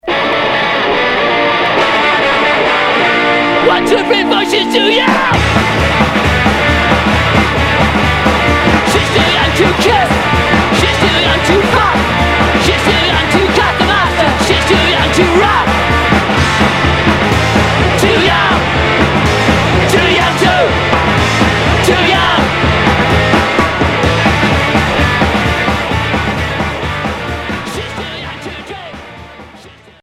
Garage punk Troisième 45t retour à l'accueil